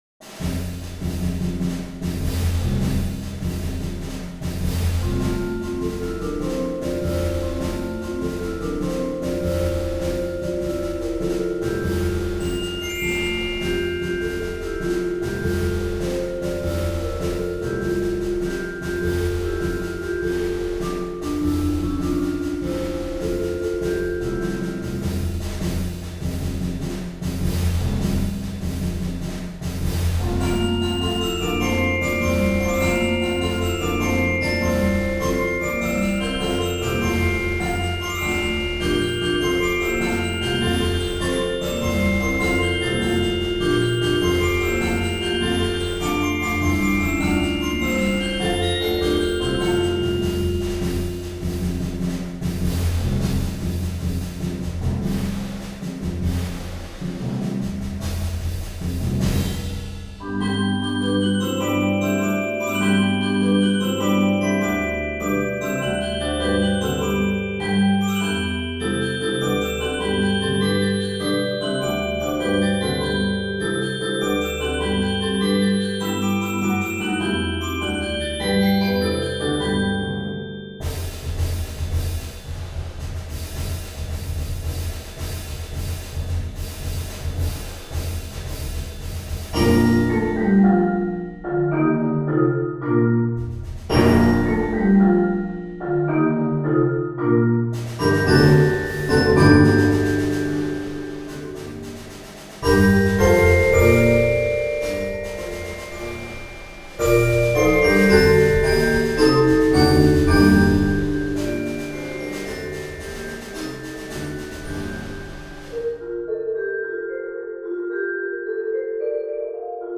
Genre: Percussion Ensemble
Bells & Chimes
Xylophone
Vibraphone 1
Marimba 1 (4-octave)*
Timpani (4 drums)
Percussion (3 players: snare drum, bass drum crash cymbals)